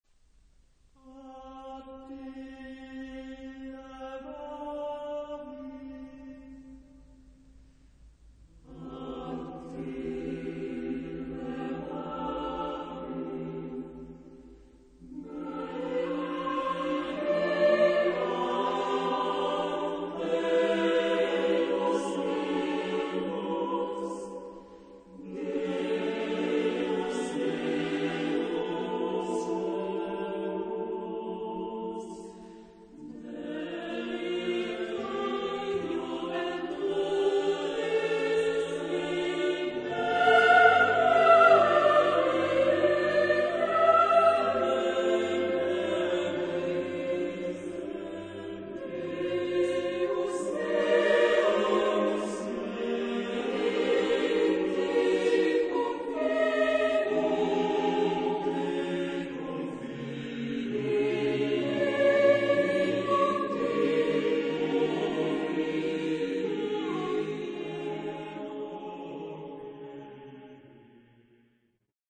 Genre-Style-Form: Sacred ; Motet
Type of Choir: SATB  (4 mixed voices )
Tonality: Tonal centers